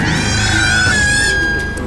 File:Giant Bat Roar GTS.ogg
Giant_Bat_Roar_GTS.ogg